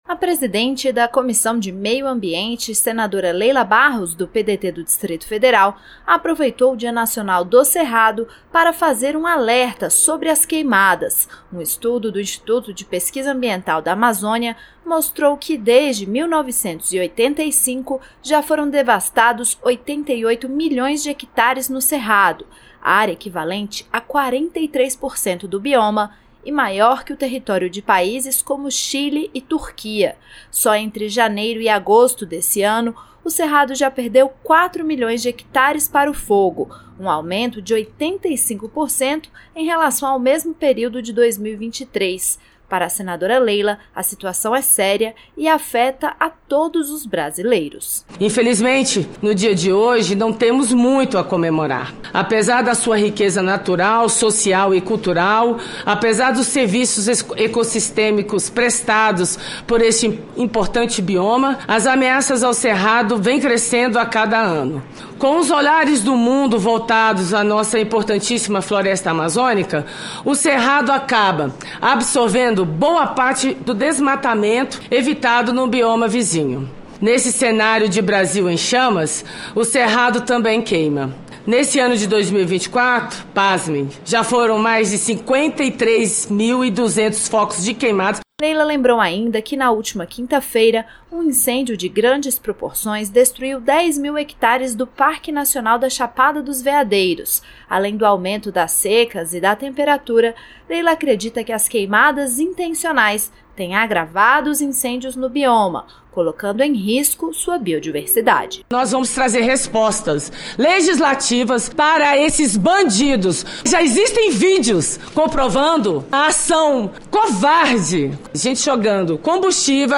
O segundo maior bioma brasileiro, também conhecido como floresta invertida, por suas raízes que chegam a 20 metros de comprimento, tem sofrido com queimadas. A presidente da Comissão de Meio Ambiente, Leila Barros (PDT-DF), lamentou que apenas em 2024 já tenham sido registrados 2024 focos de incêndios. Leila declarou que os senadores irão reagir, com soluções legislativas, diante dos casos de fogo sendo ateado de forma criminosa.